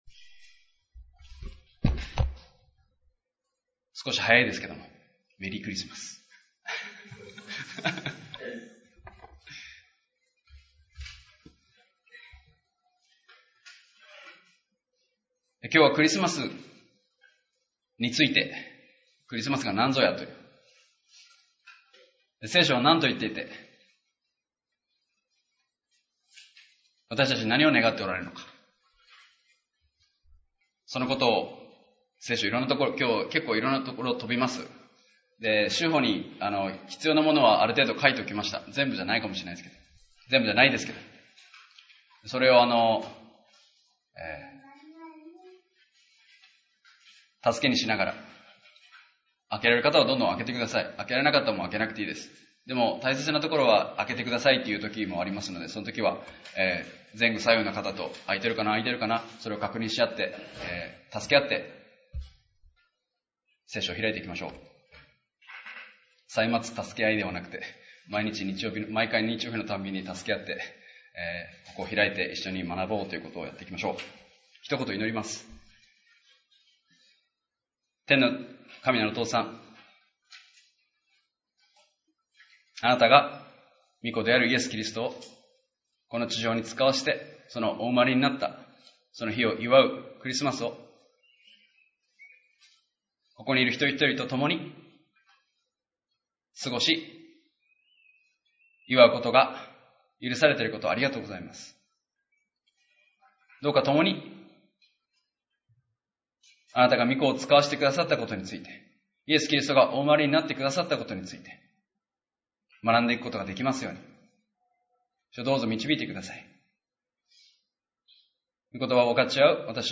礼拝やバイブル・スタディ等でのメッセージを聞くことができます。